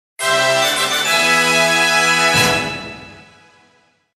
The sound that plays when completing a cup